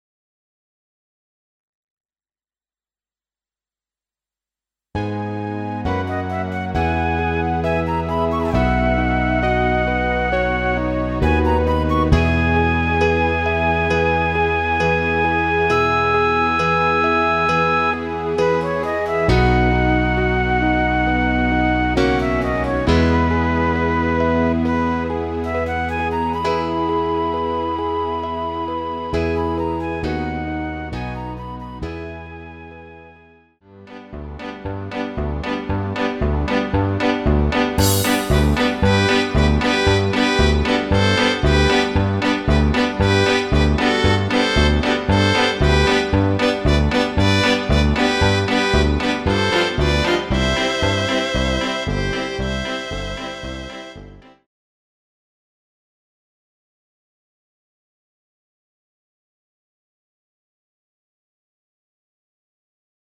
Rubrika: Pop, rock, beat
svadobný tanec (Inštrumentálka)